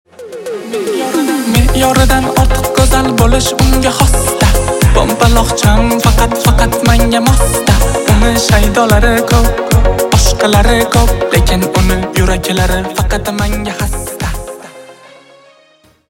восточные